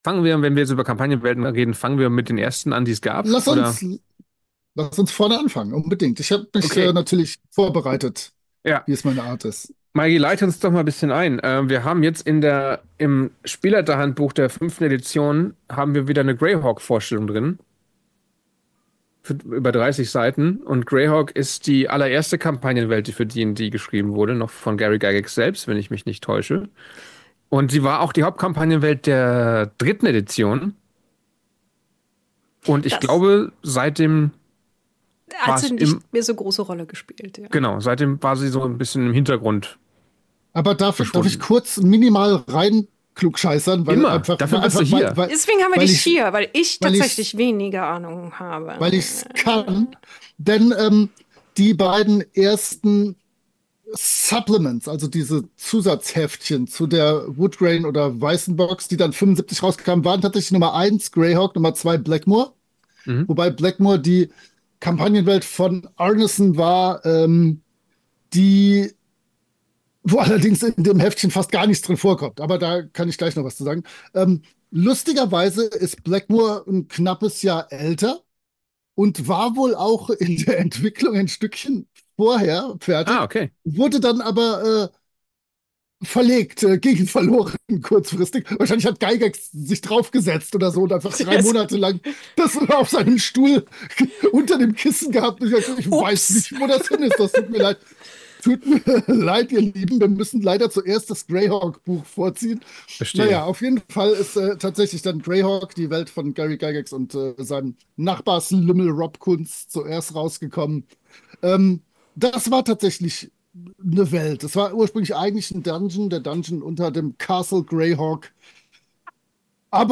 Dieser Podcast ist die unbearbeitete Tonspur eines Streams.